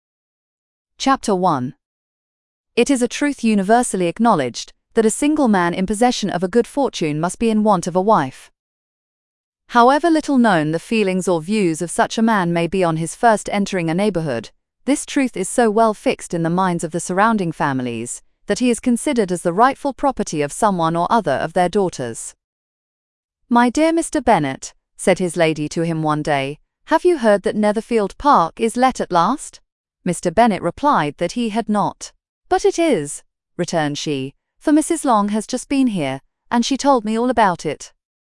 We selected the Neural voice for the three following services: Microsoft Azure, Google Gemini, Amazon Polly while selecting the standard voices for Eleven Labs’ and OpenAI.
• Google Sample:
Google_clip.mp3